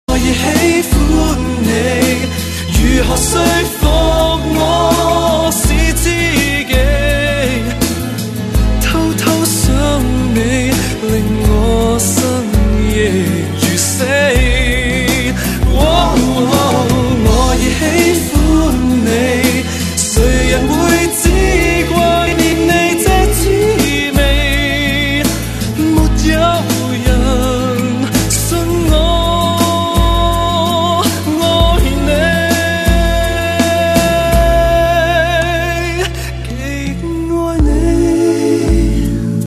M4R铃声, MP3铃声, 华语歌曲 55 首发日期：2018-05-15 04:55 星期二